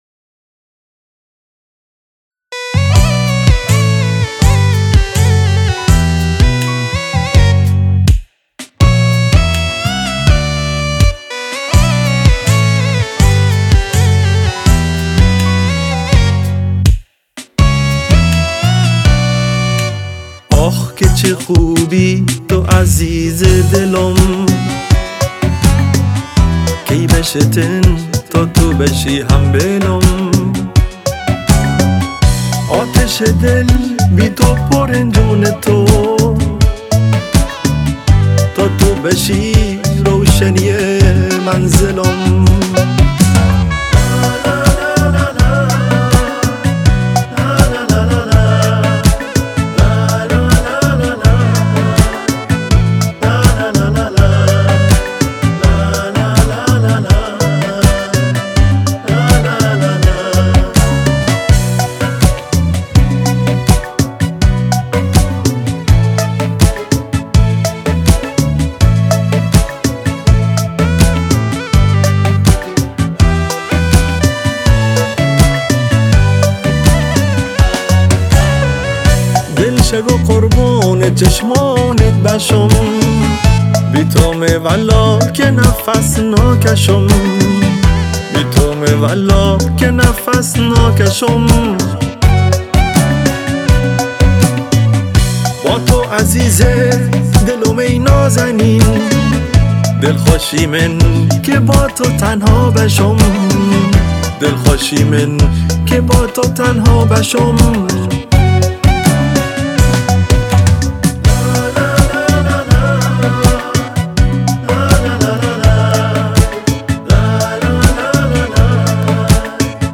تنظیم و گیتار باس